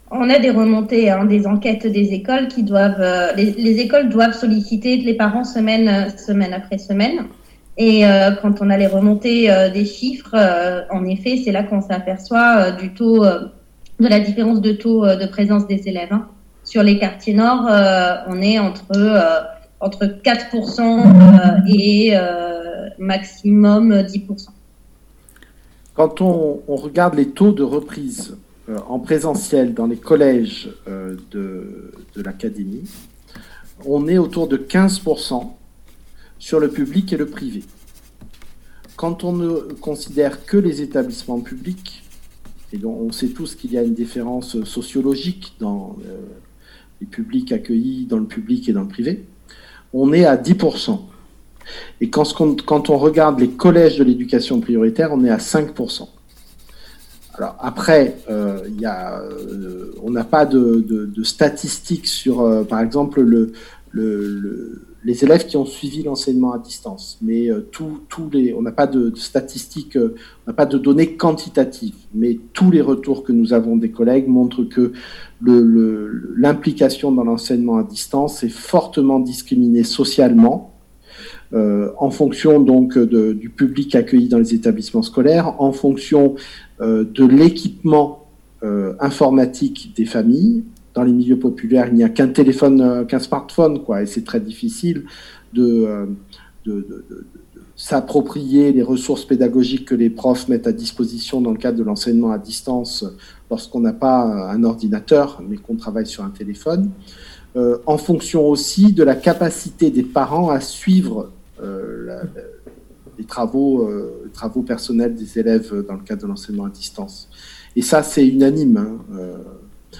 Entretiens.